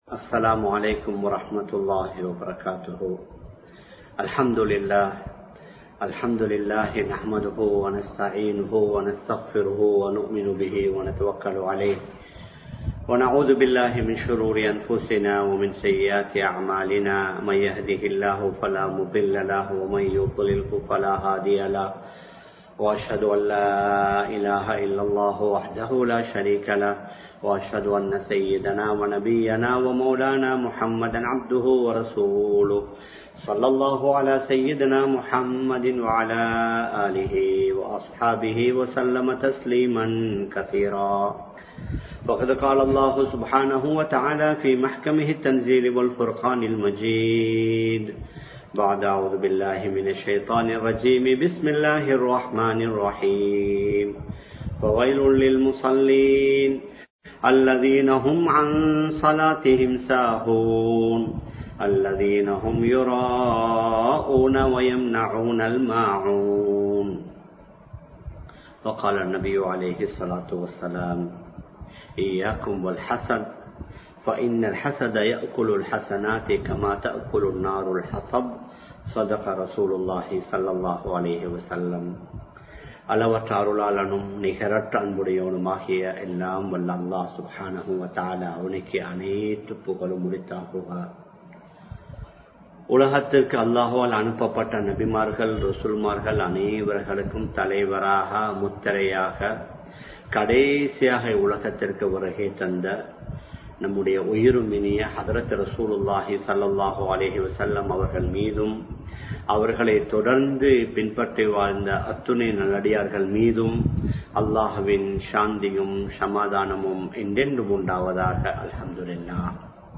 முகஸ்துதியும் பொறாமையும் (Showing off to Others and Jealousness) | Audio Bayans | All Ceylon Muslim Youth Community | Addalaichenai
Live Stream